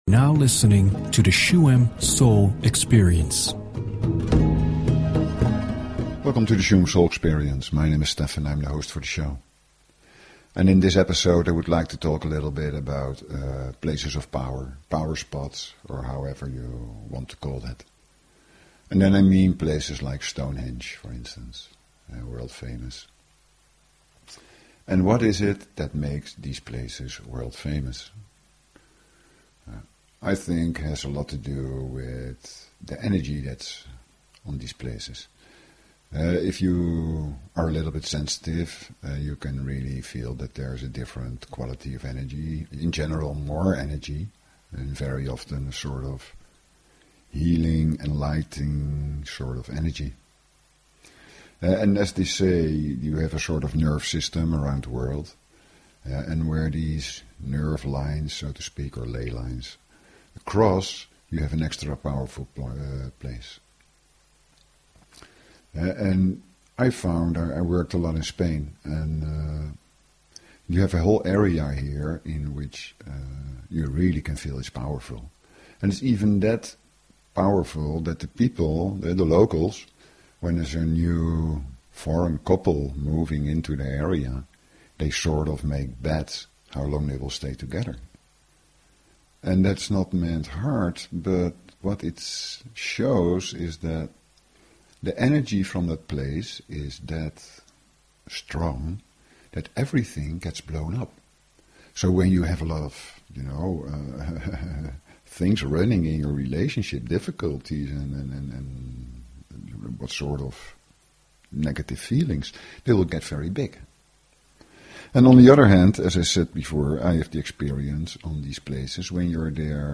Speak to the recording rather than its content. The second part of the show is a shamanic meditation ritual with a healing energy to it.